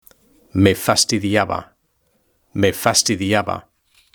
Lectura en voz alta: 3.2 Los medios de comunicación y la tecnología (H)